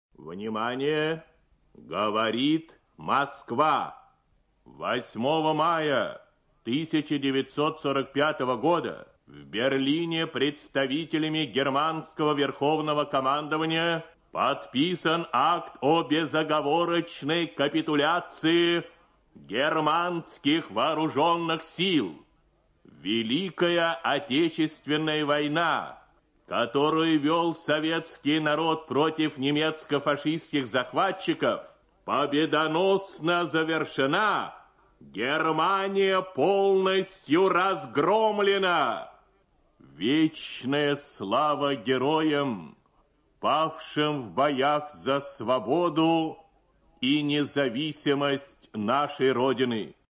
9 мая 1945 года в 2 часа 10 минут по московскому времени диктор Юрий Левитан объявил о капитуляции фашистской Германии и зачитал указ об объявлении этого дня праздником Победы.
Легендарная речь Юрия Левитана об окончании Великой Отечественной войны
9 мая 1945 года, в 6 часов утра по московскому времени, главный голос страны Юрий Левитан зачитал приказ №369 о победе Советского Союза над Германией.
legendarnaya-rech-yuriya-levitana-ob-okonchanii-velikoy-otechestvennoy-voynyi.mp3